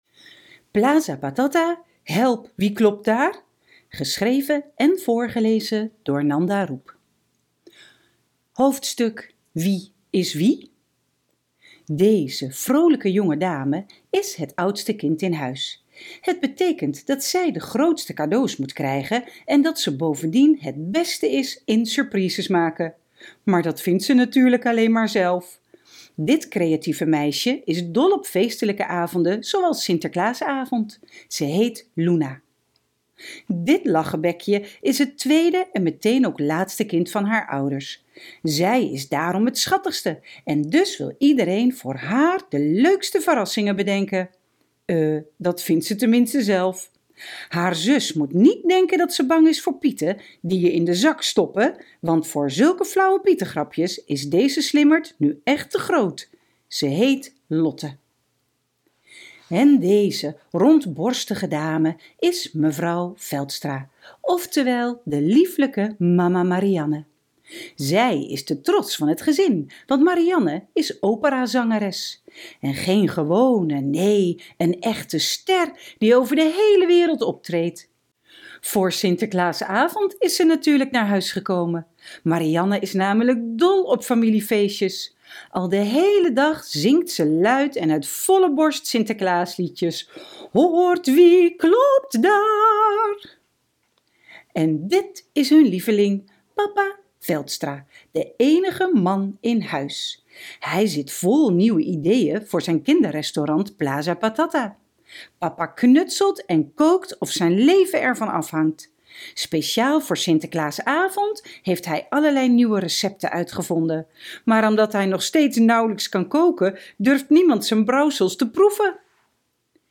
luisterboek